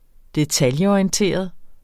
Udtale [ -ɒiənˌteˀʌð ]